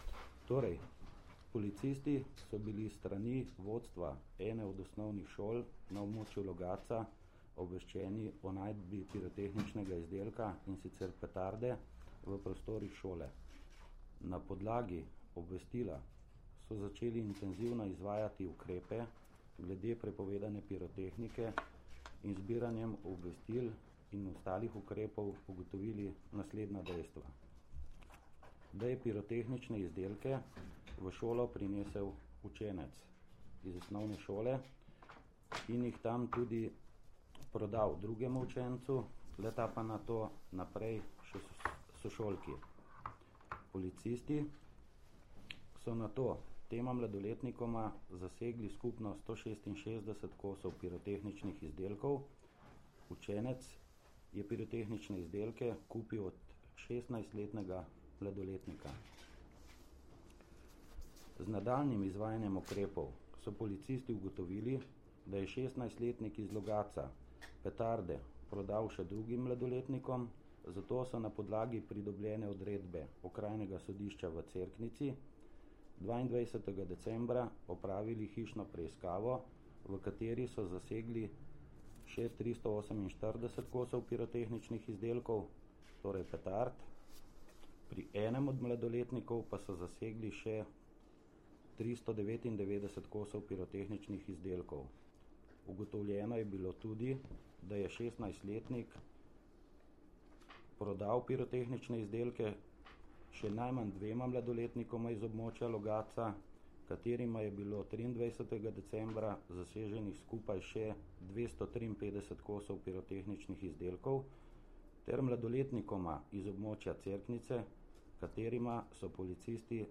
Več o zasegih sta na današnji novinarski konferenci povedala
Zvočni posnetek izjave